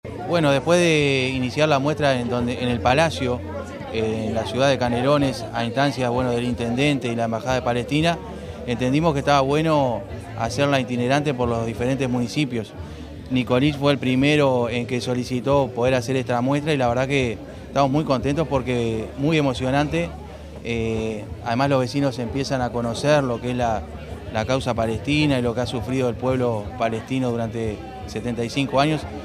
Director General de Descentralización y Participación, Rubén Moreno, durante su oratoria